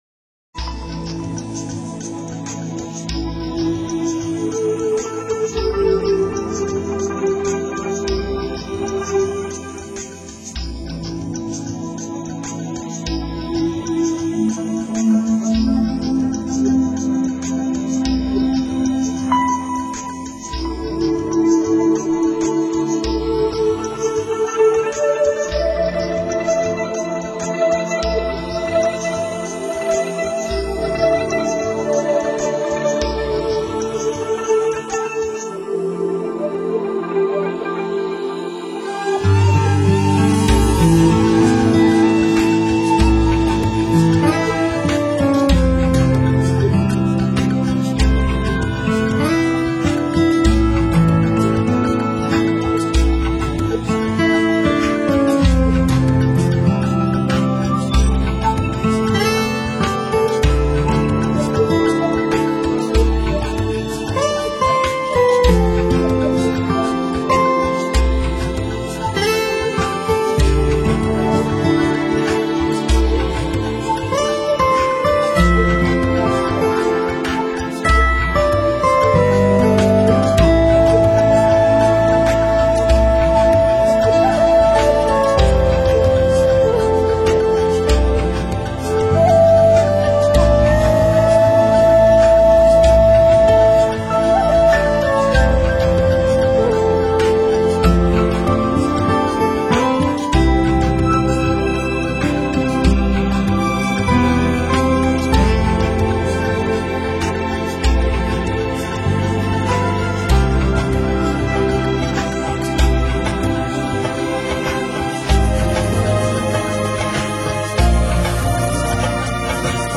打开双耳，听那水的自在流动与风的轻快呼吸。
小调式的民谣风与东方情味谱出了水岸独有的浪漫，
充满异想的旋律线条搭配轻盈的节奏感，